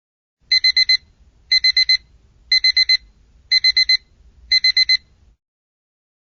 alarm.DZEFjlyU.mp3